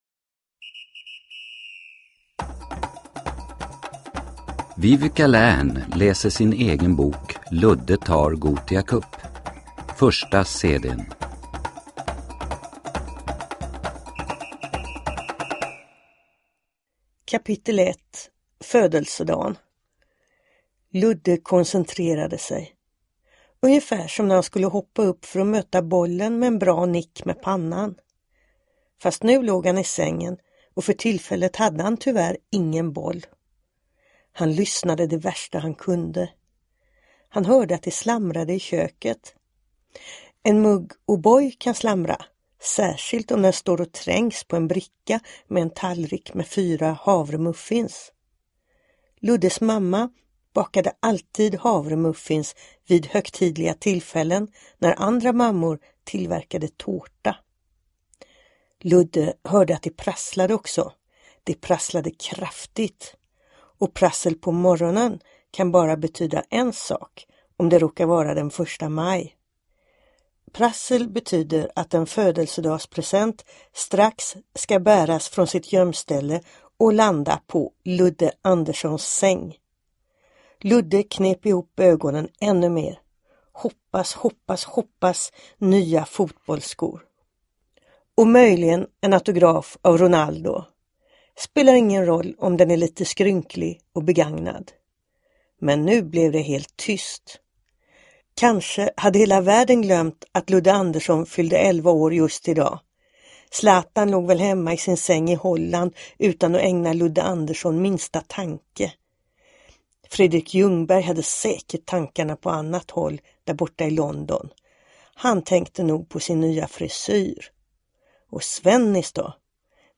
Ludde tar Gothia cup – Ljudbok – Laddas ner